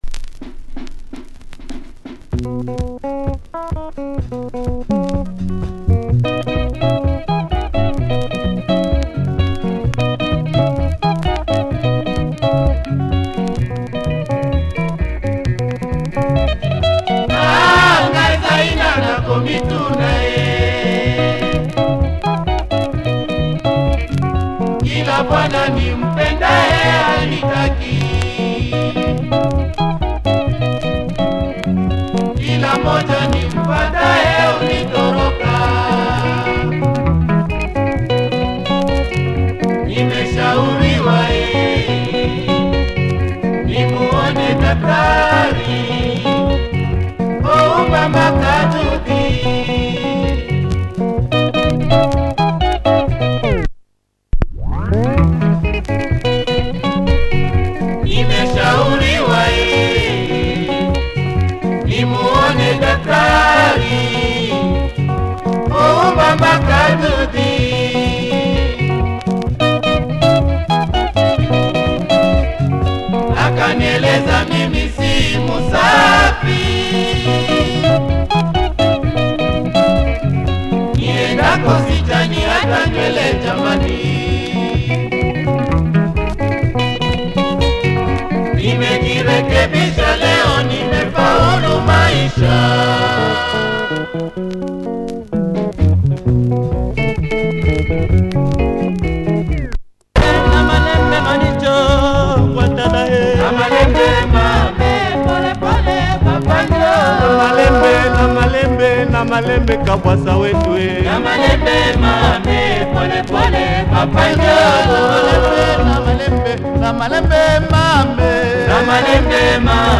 Nice Lingala, nice breakdown.